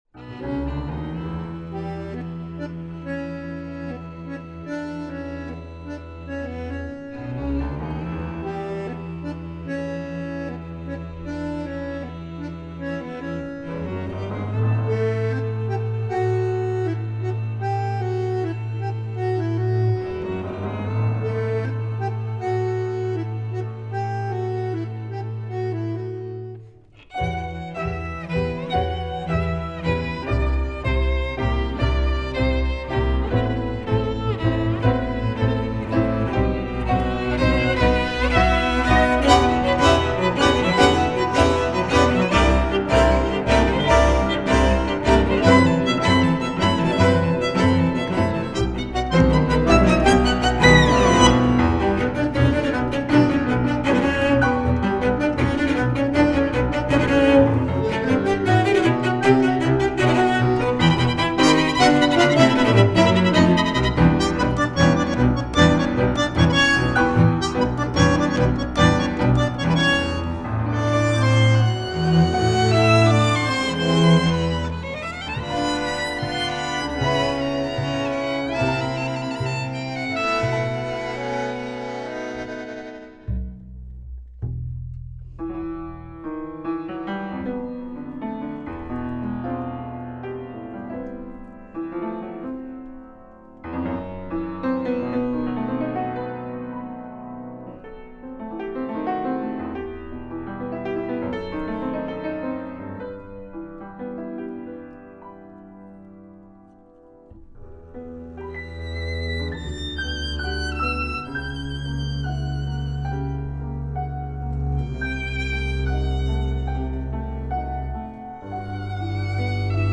группа / Москва / латино / джаз